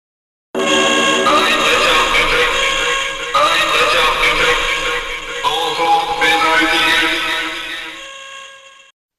DIÁLOGOS